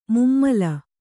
♪ mummala